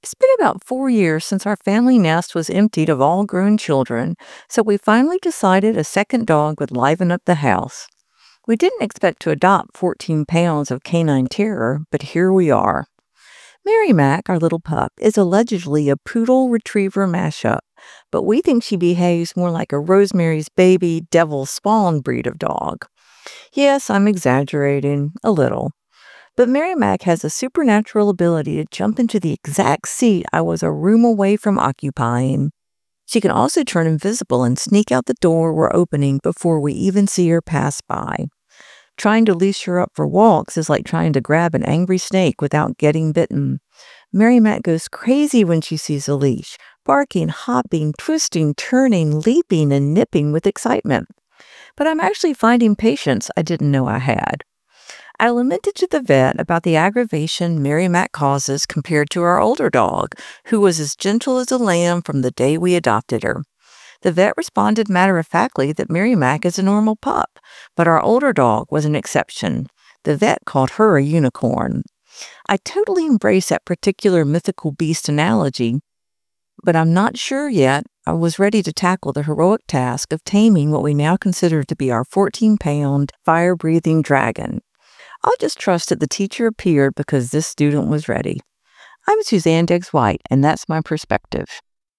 Perspectives are commentaries produced by and for WNIJ listeners, from a panel of regular contributors and guests.